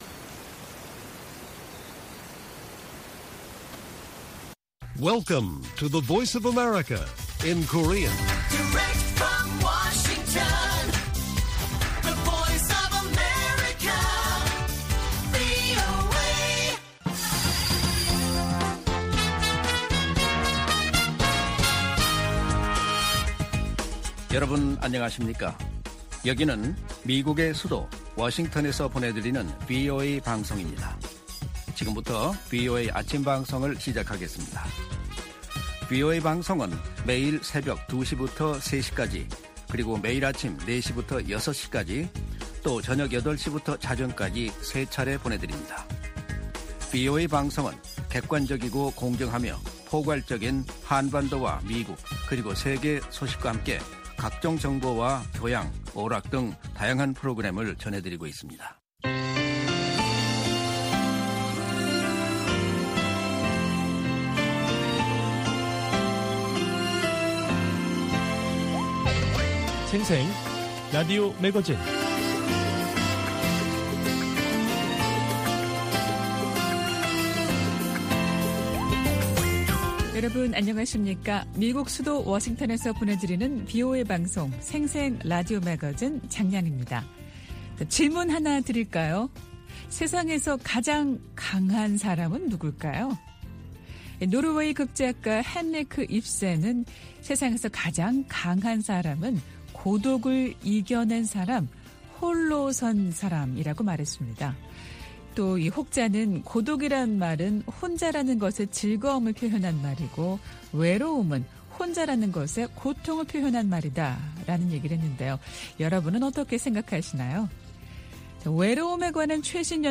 VOA 한국어 방송의 월요일 오전 프로그램 1부입니다. 한반도 시간 오전 4:00 부터 5:00 까지 방송됩니다.